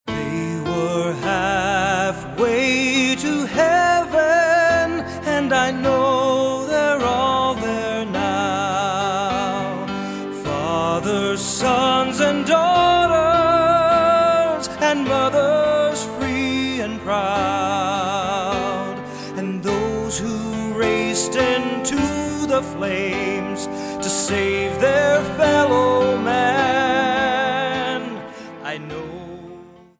American Patriotic 9/11 Song Lyrics and Sound Clip